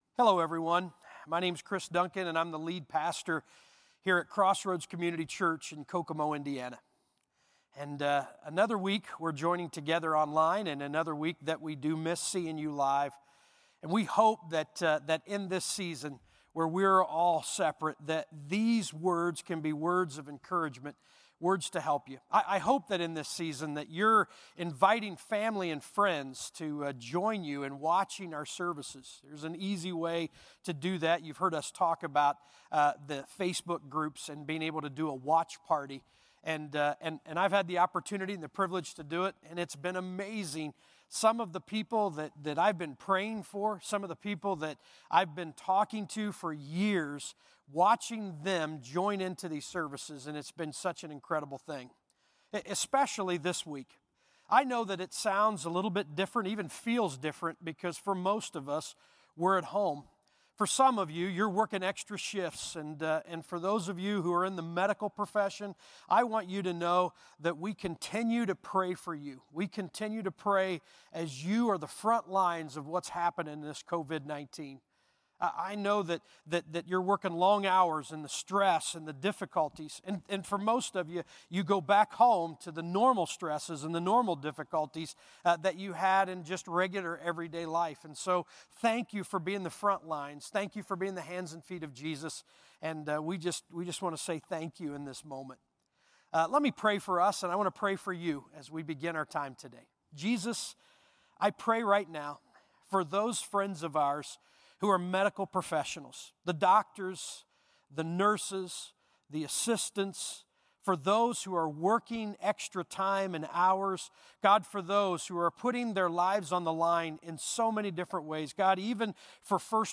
Crossroads Community Church - Audio Sermons 2020-04-05 - Jesus Is …